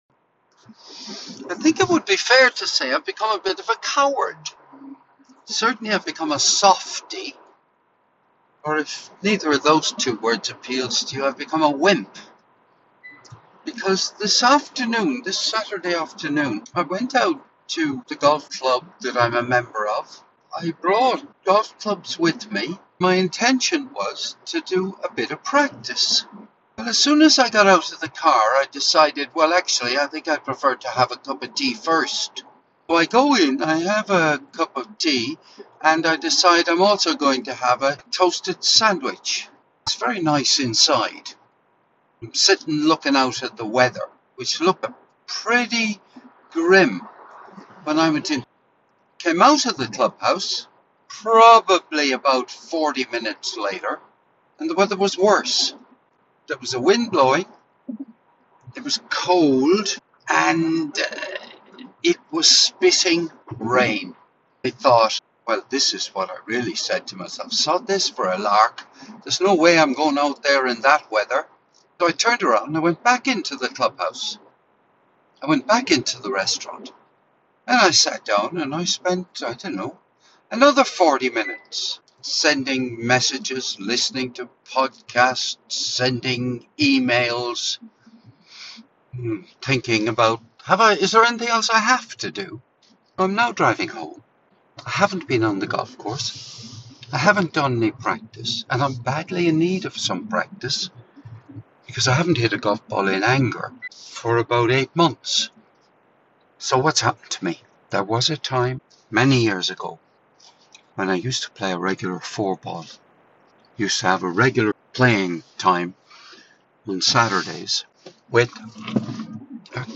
BIRDSONG WITH MERLIN